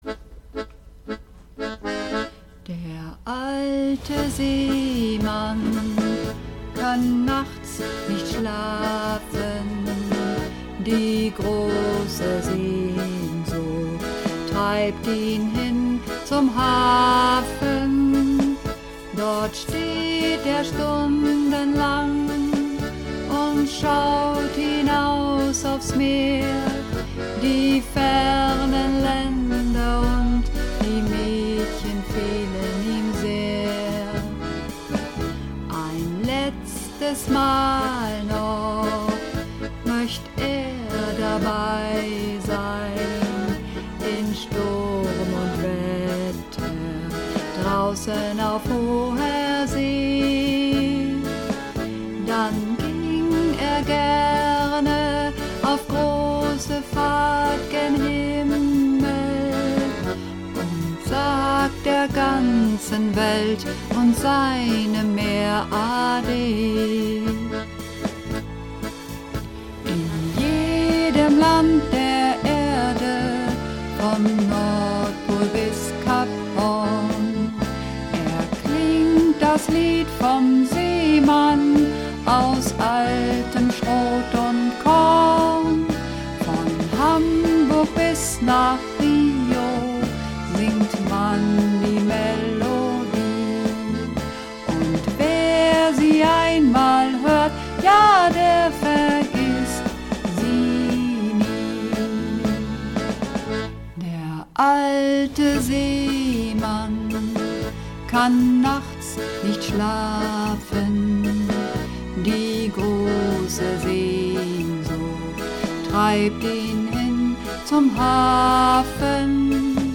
Übungsaufnahmen - Der alte Seemann
Der alte Seemann (Alt)
Der_alte_Seemann__1_Alt.mp3